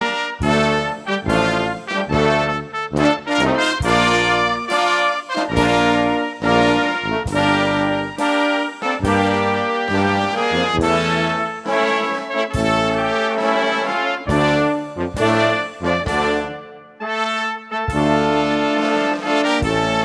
Interpret: dech. orchestr